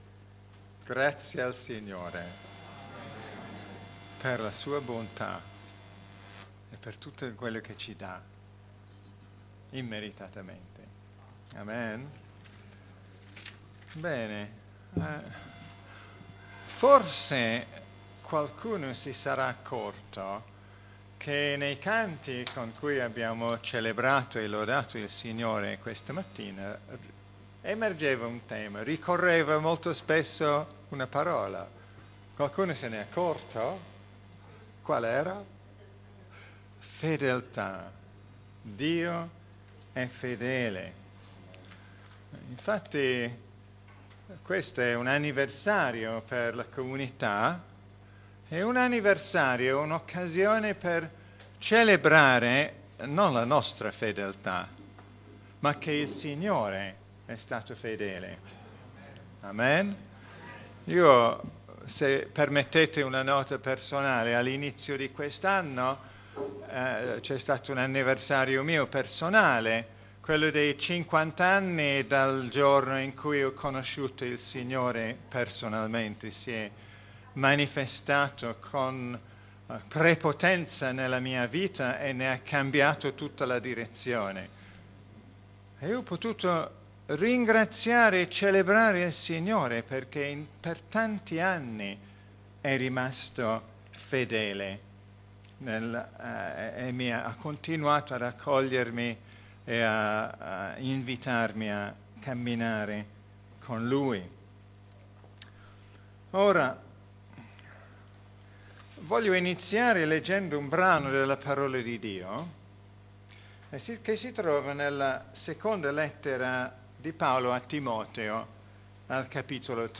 In occasione del compleanno della chiesa
Buon ascolto! 0:00 ( Clicca qui se vuoi scaricare il file mp3 ) ‹ Vivere da figli di Dio L’amore di Dio dentro di noi › Pubblicato in Messaggio domenicale